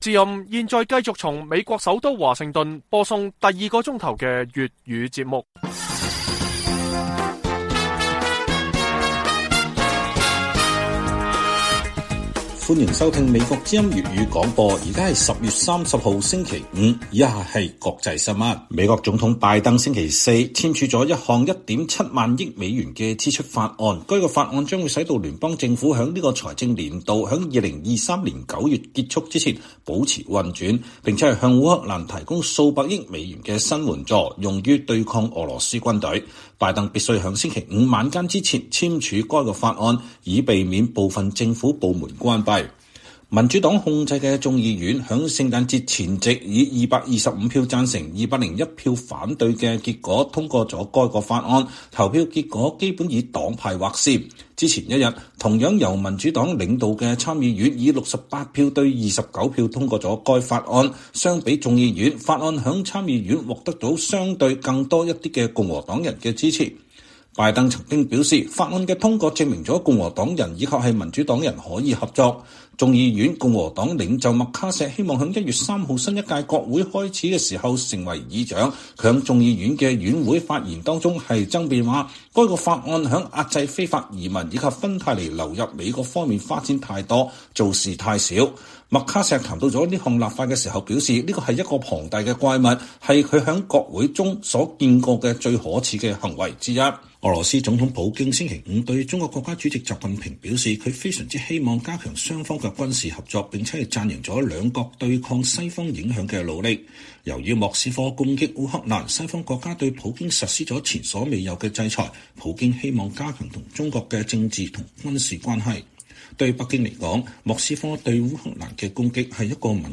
粵語新聞 晚上10-11點: 拜登簽署2023財政年度1.7萬億美元支出法案